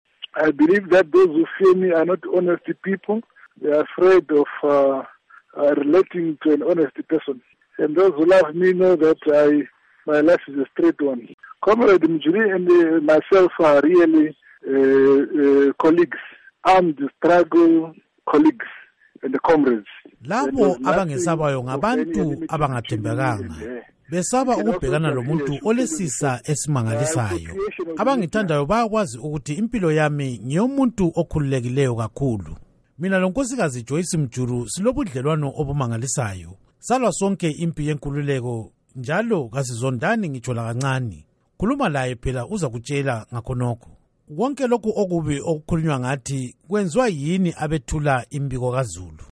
Ingxoxo loMnu. Emmerson Mnangagwa